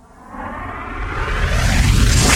VEH1 Reverse - 32.wav